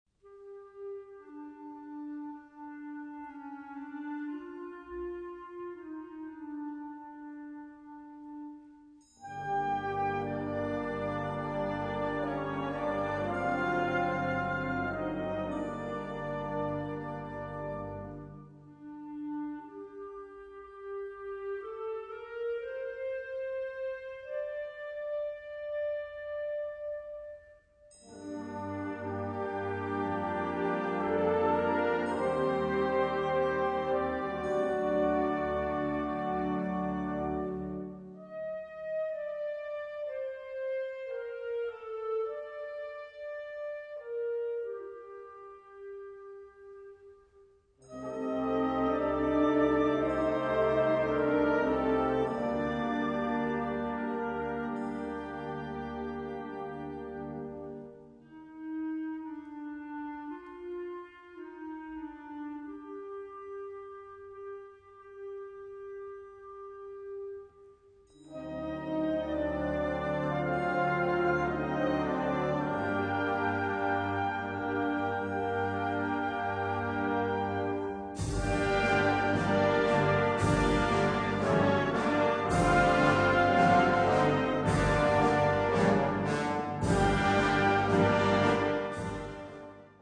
Categoria Concert/wind/brass band
Sottocategoria Suite
Instrumentation Ha (orchestra di strumenti a faito)